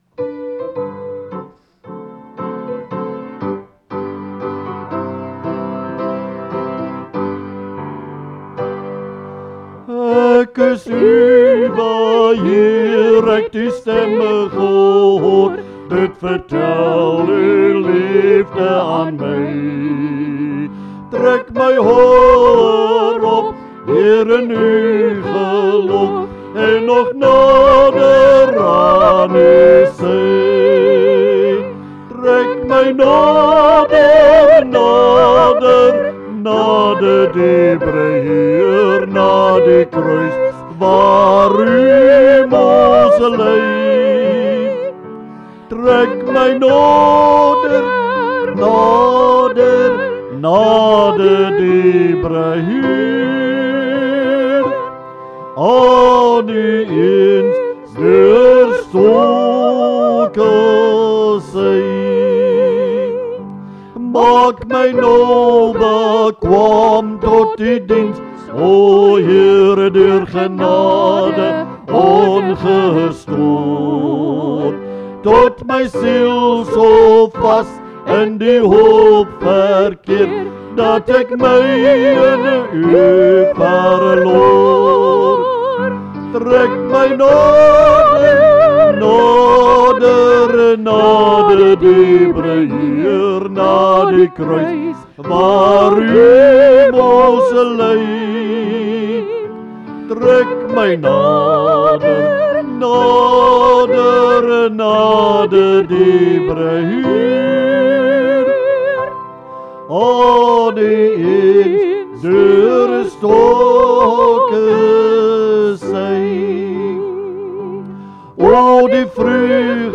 Liedere - Begeleiding en samesang
Dit is geensins professionele opnames nie.